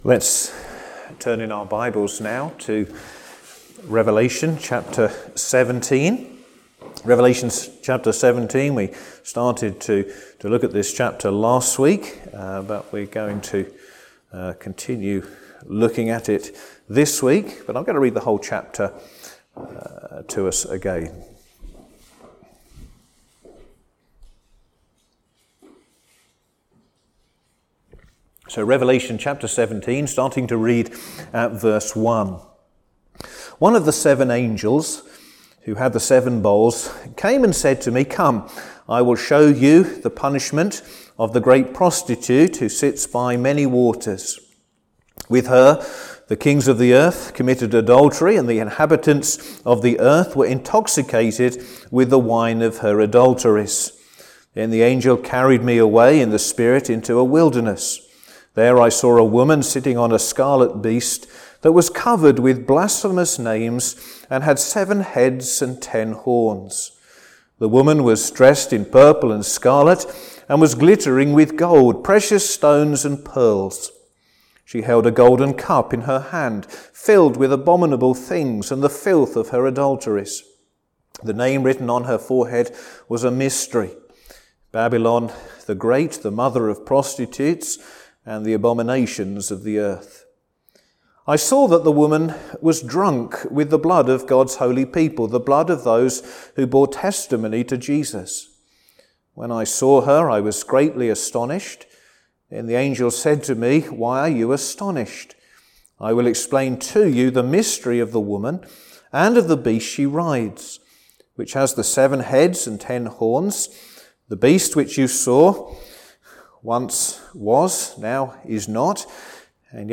Sermons
Service Evening